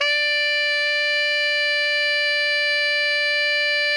TENOR FF-D5.wav